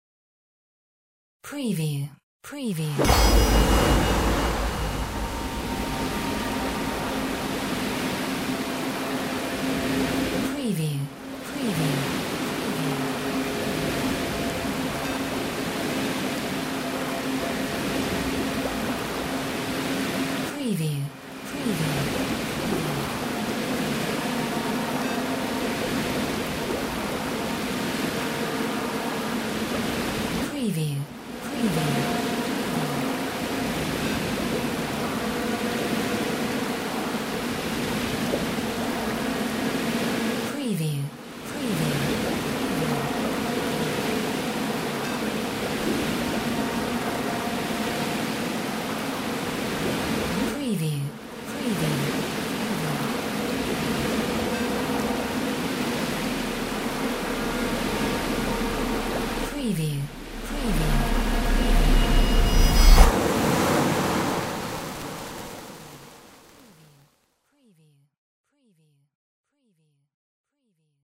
Stereo sound effect - Wav.16 bit/44.1 KHz and Mp3 128 Kbps
previewSCIFI_MAGIC_WATER_FULL_WBHD05.mp3